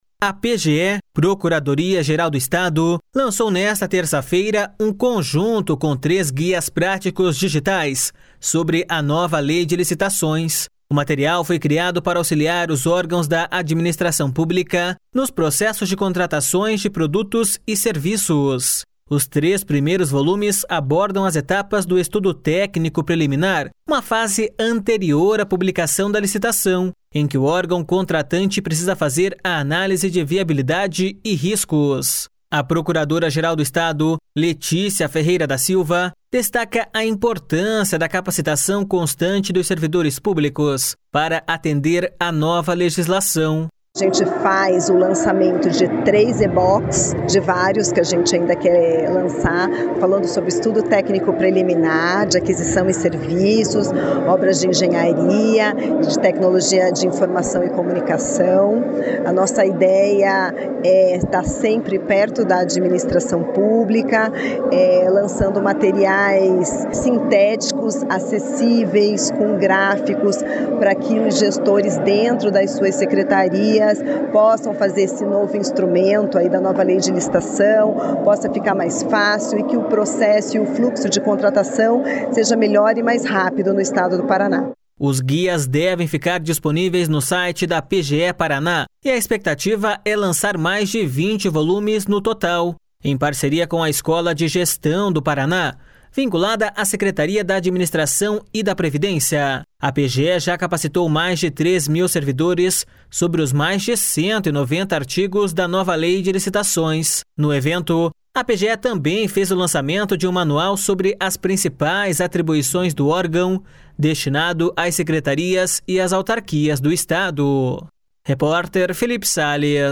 A Procuradora-Geral do Estado, Leticia Ferreira da Silva, destaca a importância da capacitação constante dos servidores públicos para atender a nova legislação.// SONORA LETICIA FERREIRA DA SILVA.//